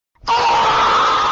Screaming Emoji Turns To Dust